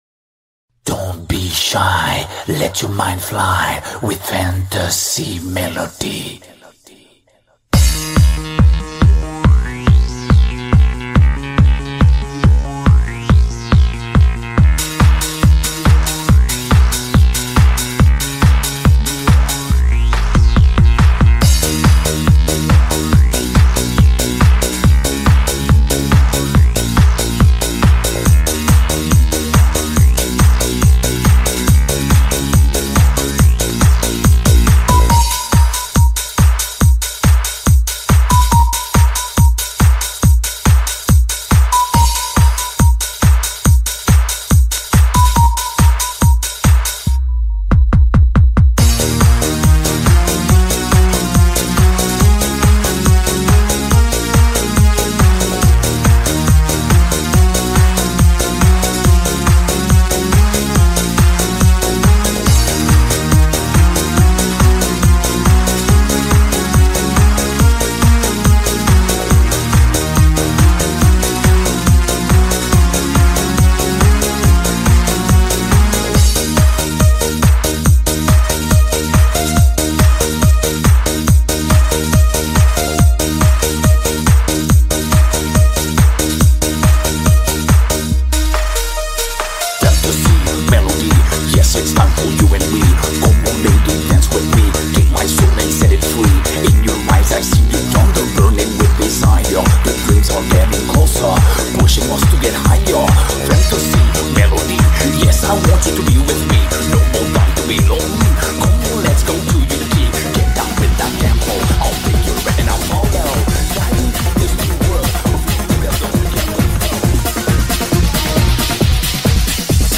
Euro Dance Fraktal Best Rare